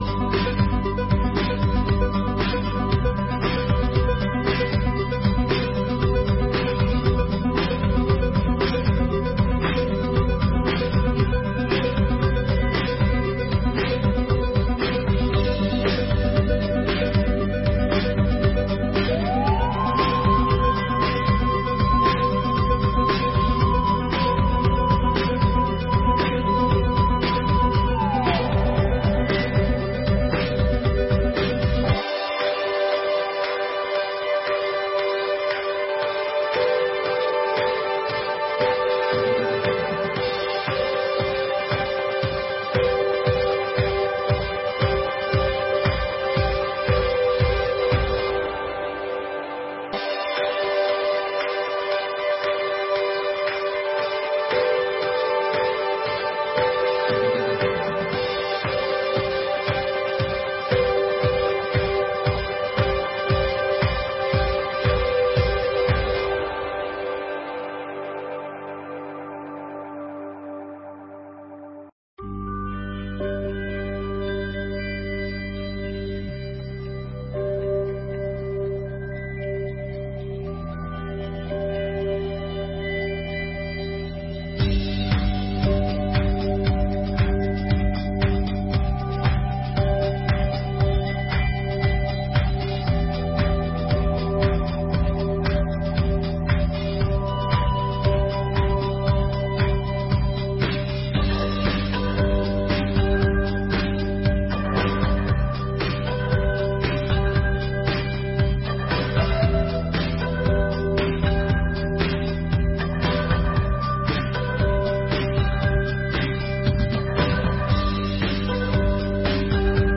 Audiência Pública: Discussão do Projeto de Lei nº 73/2022